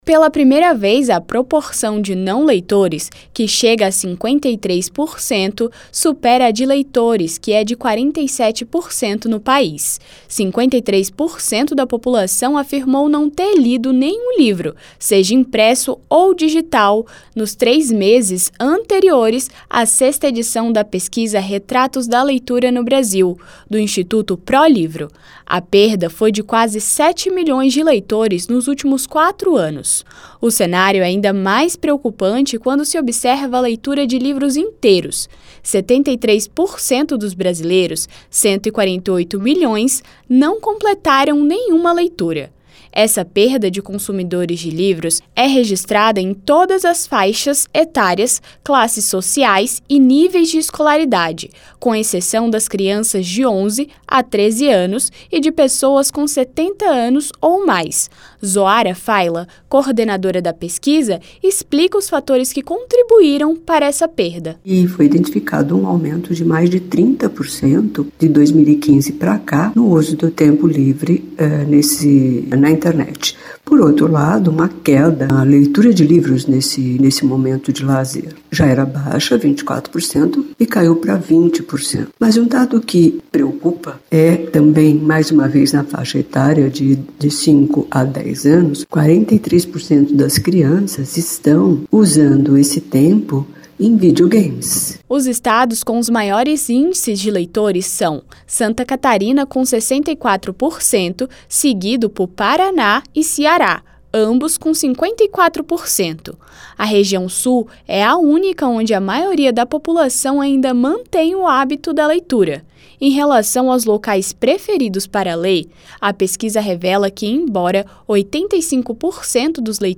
Matéria Publicada na Rádio Senado